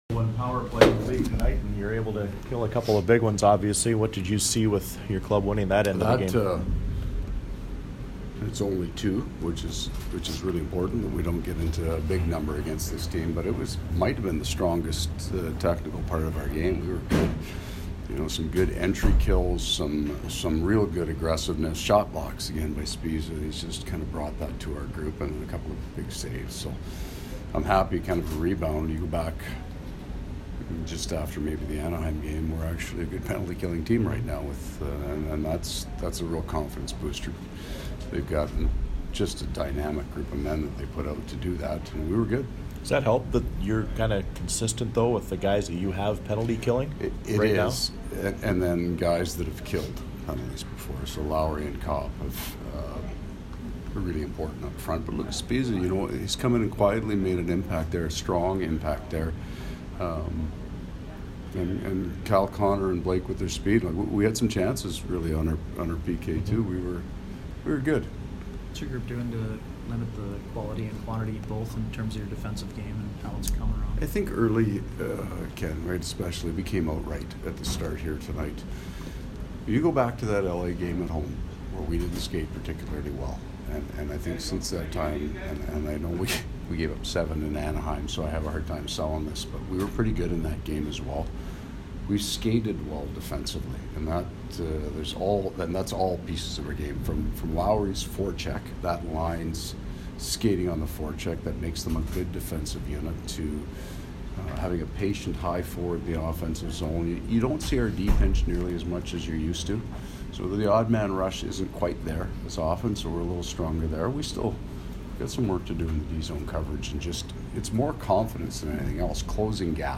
Paul Maurice post-game 11/ 16